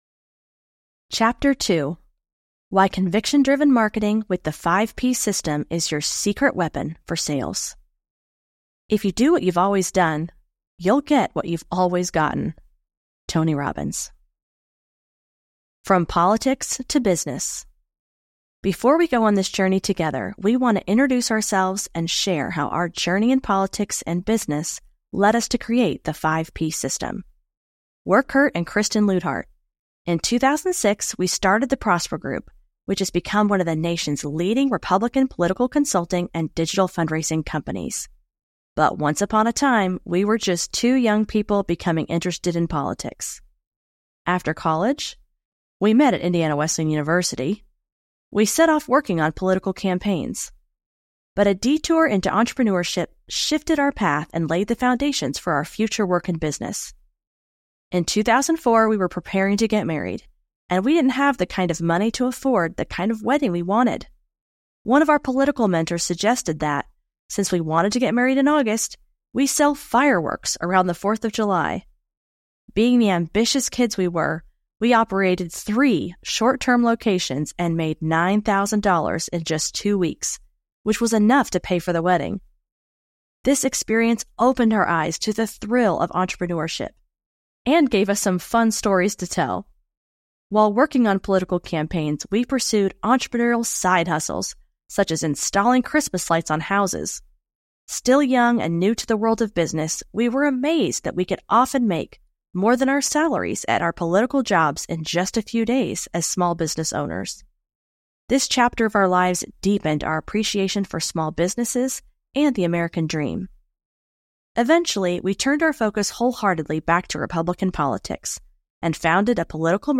AudioBook - Liberty Spenders